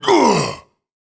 Jin-Vox_Dead2_kr.wav